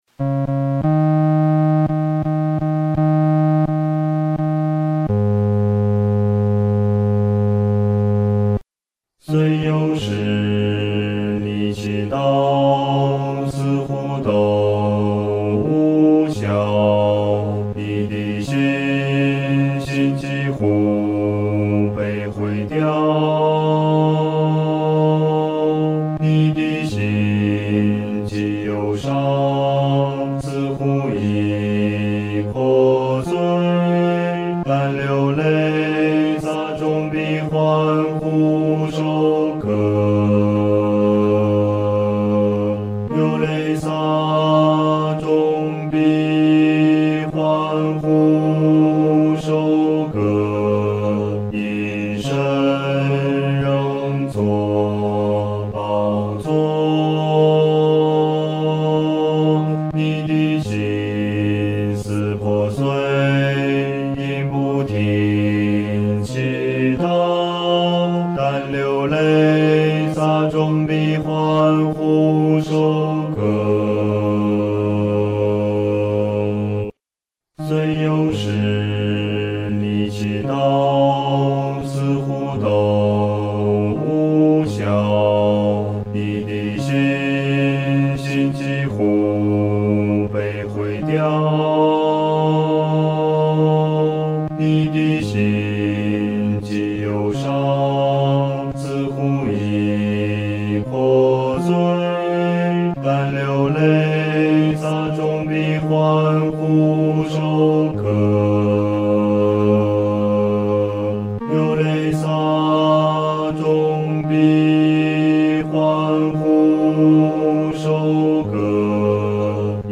合唱
男低